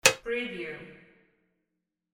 Button Rollover Sound Effect #26
Description: Button rollover sound effect for flash animations, websites, games, video productions, etc.
Keywords: button, rollover, roll, over, interface, flash, game, multimedia, animation, software, application, menu, navigation, click, alert, switch